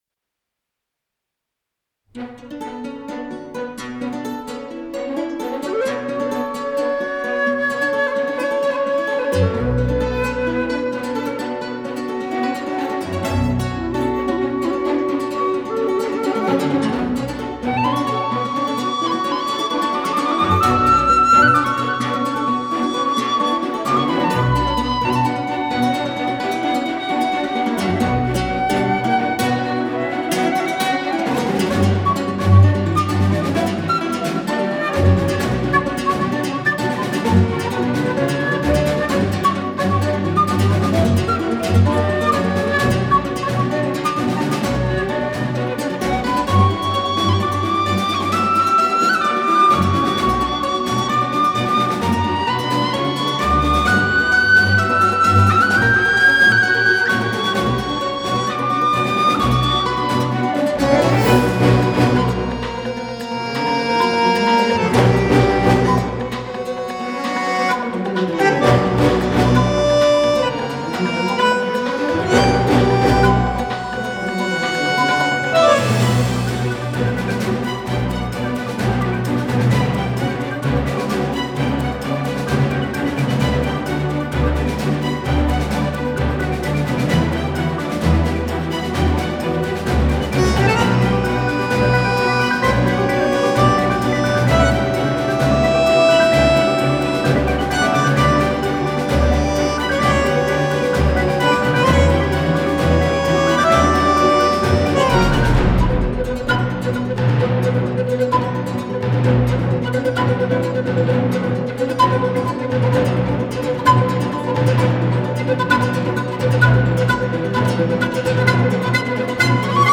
Музло из битвы с русичем
РУССКАЯ НАРОДНАЯ БЛАТНАЯ ХОРОВОДНАЯ